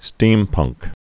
(stēmpŭngk)